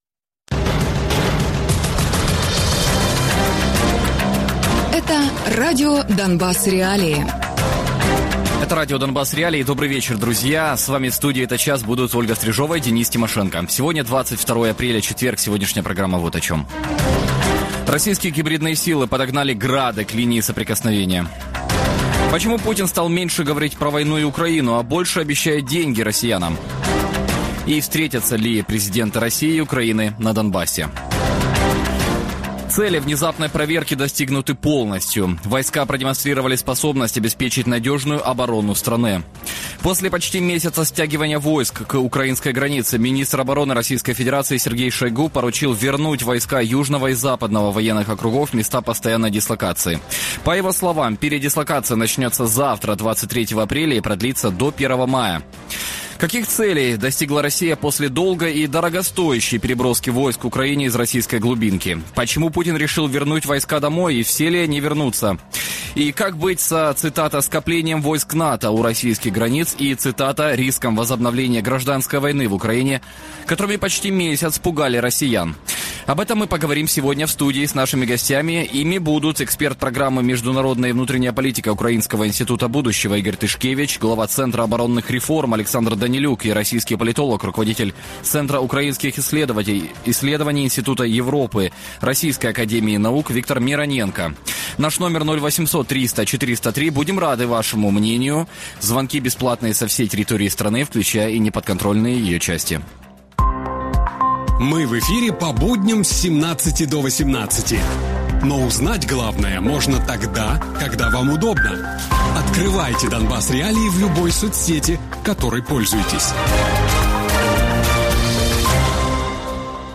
Гості програми радіо Донбас.Реалії